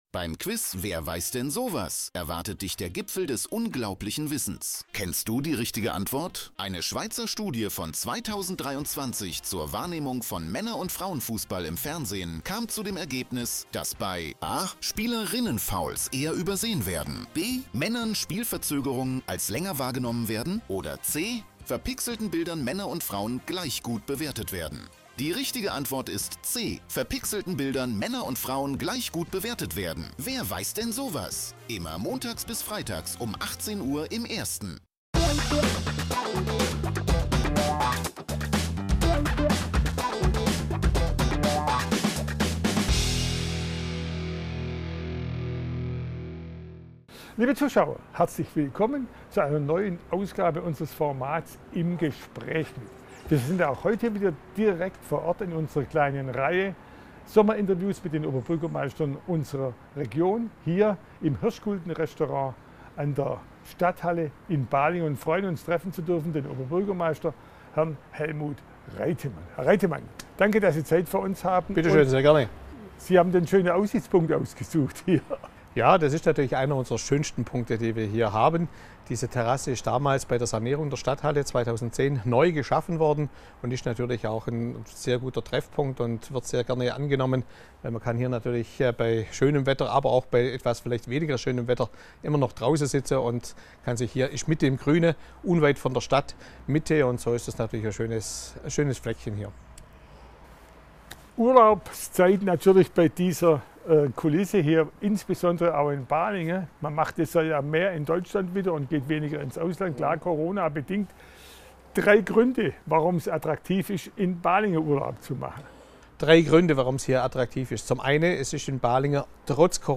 Helmut Reitemann, OB Balingen, im Sommerinterview ~ RTF1 Neckar-Alb Podcast | Reutlingen Tübingen Zollernalb Podcast
Das Sommerinterview 2020 mit Helmut Reitemann, Oberbürgermeister von Balingen im Zollernalbkreis.